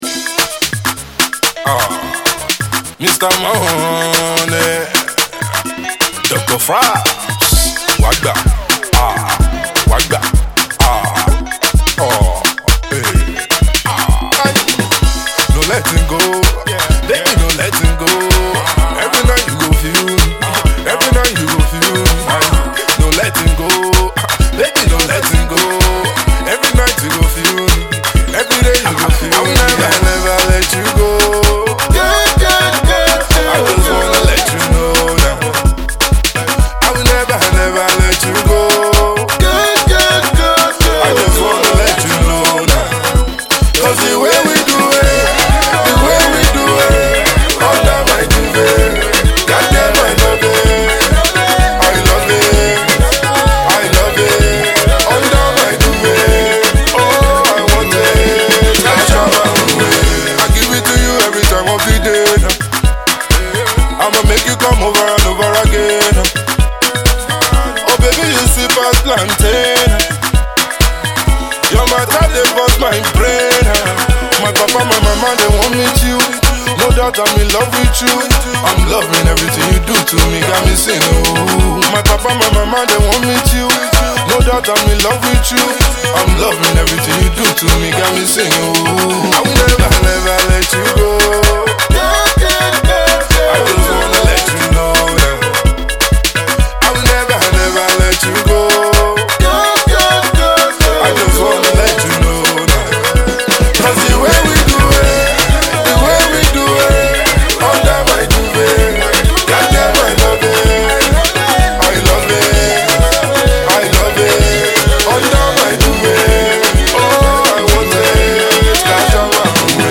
Afro Hip-Hop sound